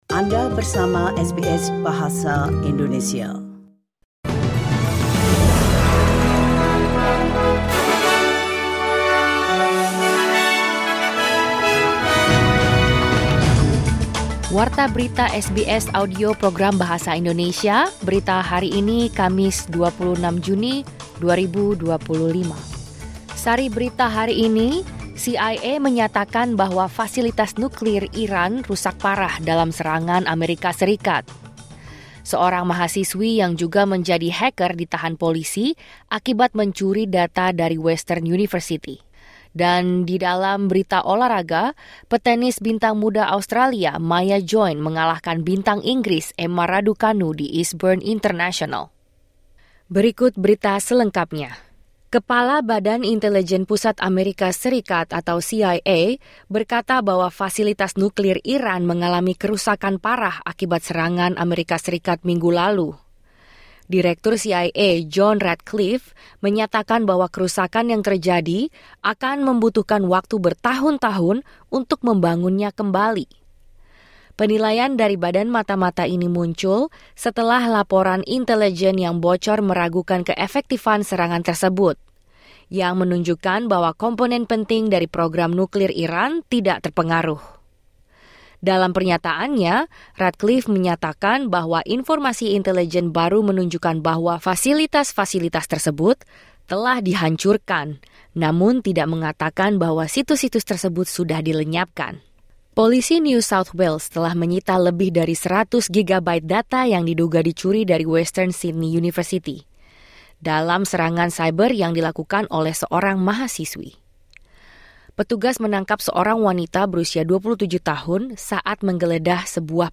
Berita Terkini SBS Program Bahasa Indonesia - 26 Juni 2025